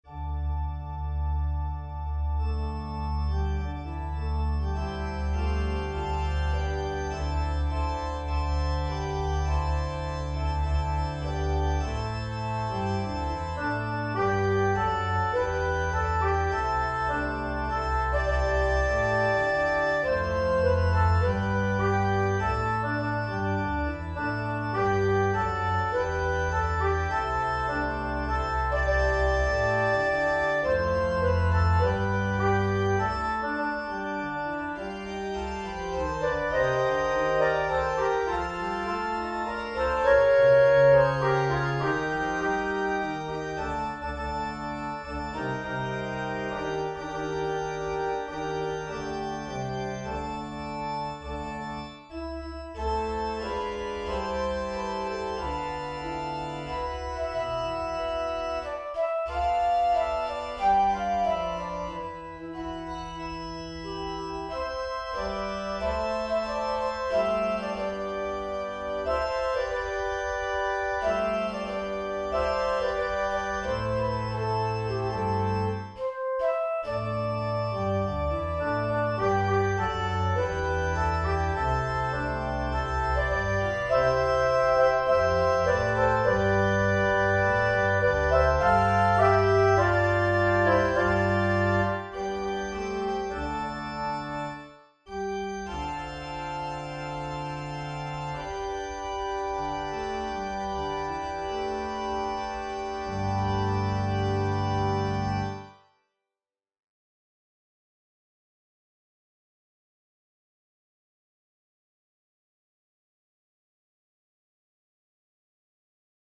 for SATB Choir